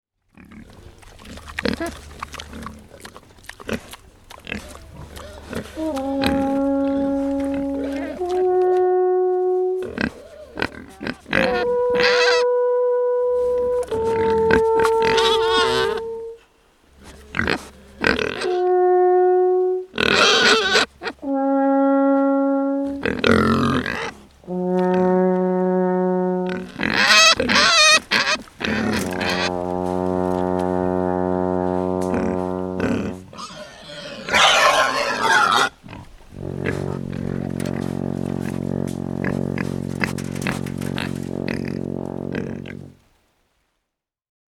Recorded at the Haliburton Forest during a conference of the Canadian Association for Sound Ecology, October 2004.
Alphorn
horn_and_hog.mp3